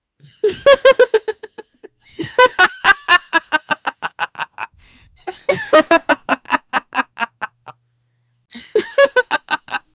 young couple laughing together outdoors. Natural, cheerful, romantic laughter — male and female voices laughing joyfully with warmth and playfulness. No background noise, clean and realistic recording. 0:10 wedding song instrumental happines 0:10
-young-couple-laughing-to-ipadbteo.wav